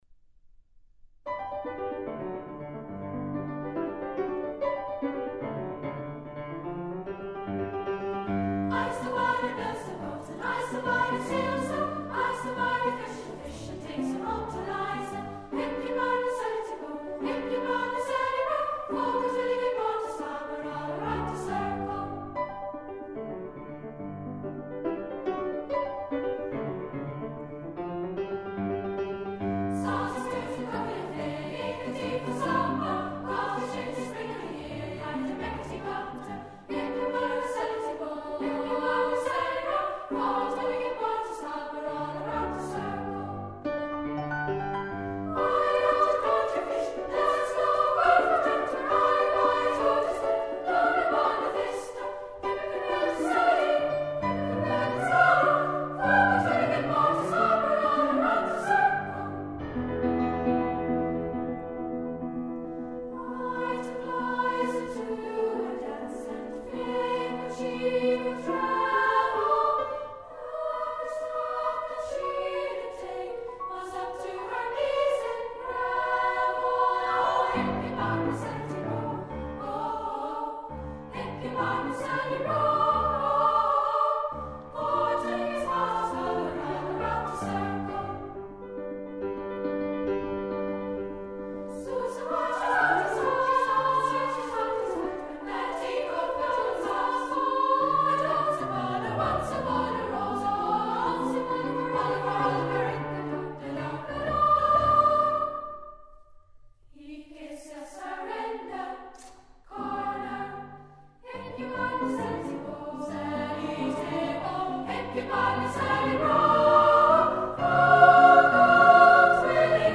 the children's choir of the Musicschool in Enschede. I'se the B'y . Newfoundland folksong, arr. John E. Govedas.